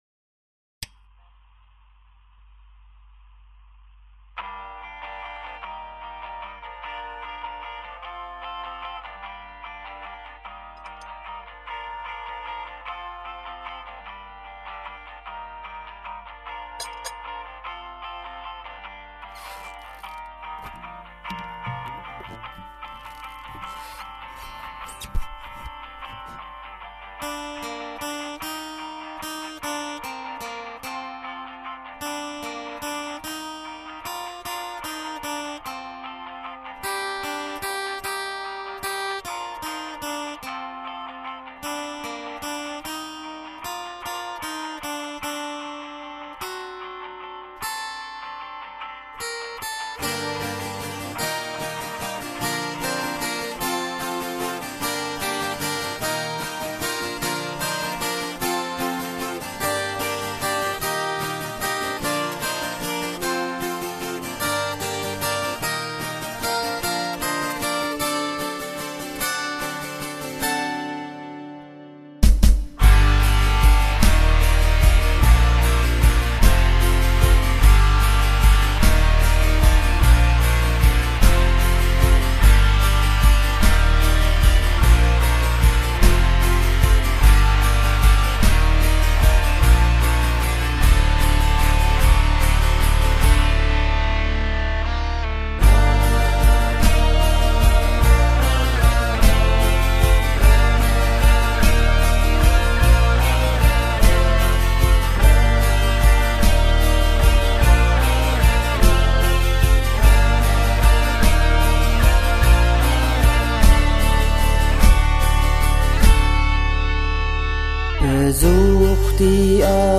Sehnsucht (Demo) (C) 2011zur Übersicht
Gesang